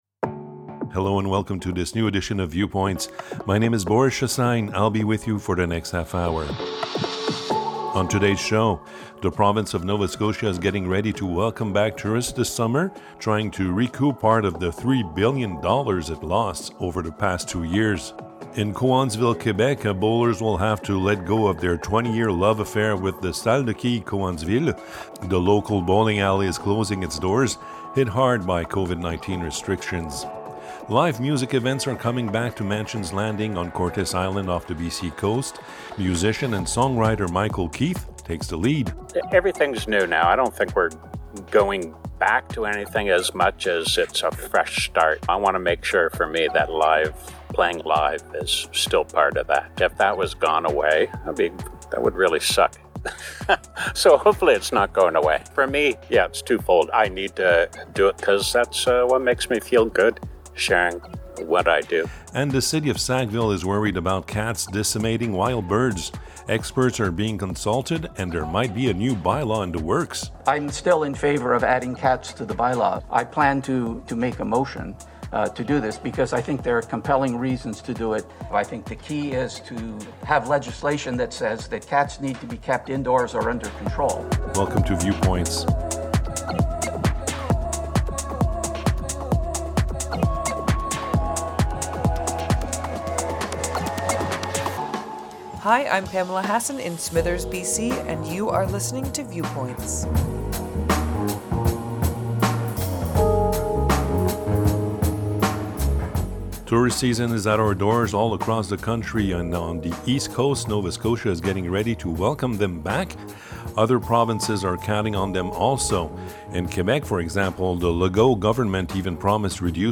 Viewpoints, Episode 11 Viewpoints is a half-hour magazine aired on 30 radio stations across Canada.